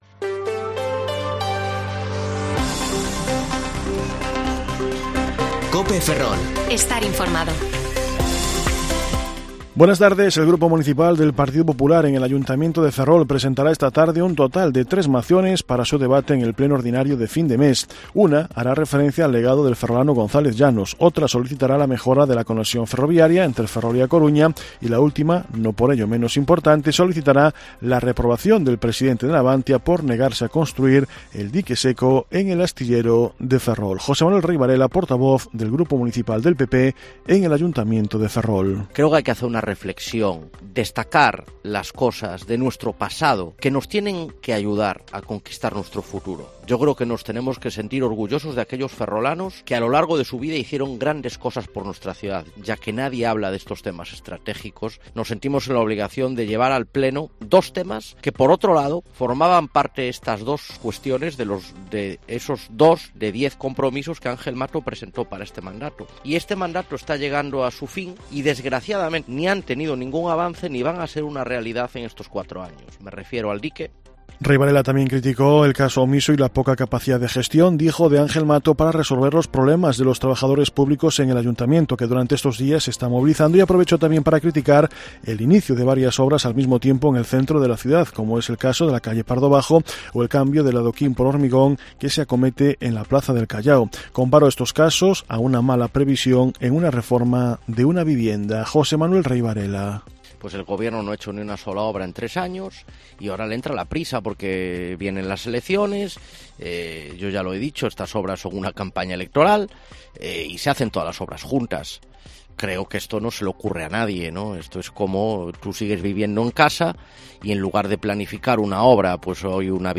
Informativo Mediodía COPE Ferrol 28/4/2022 (De 14,20 a 14,30 horas)